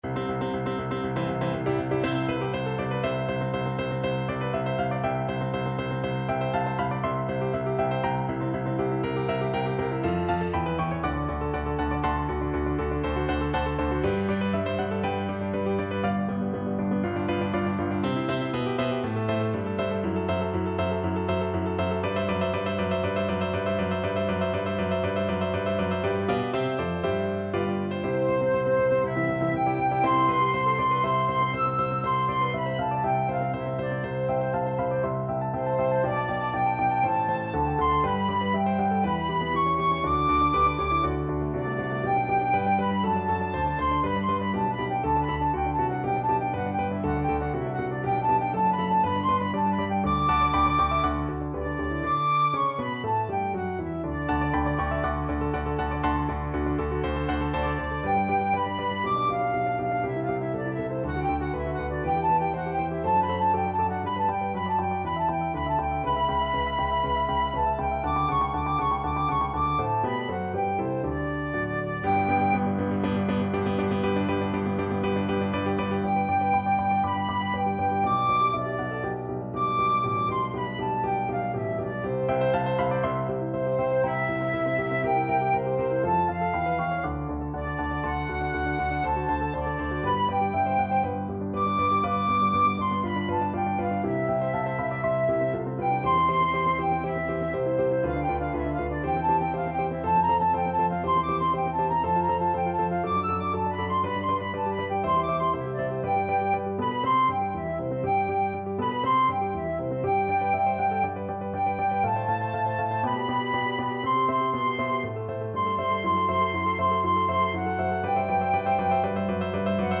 C major (Sounding Pitch) (View more C major Music for Flute )
~ = 100 Molto allegro =c.120
4/4 (View more 4/4 Music)
B5-E7
Flute  (View more Intermediate Flute Music)
Classical (View more Classical Flute Music)